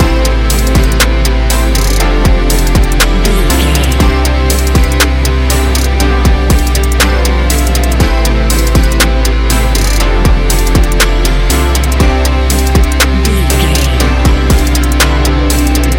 Ionian/Major
ambient
downtempo
space music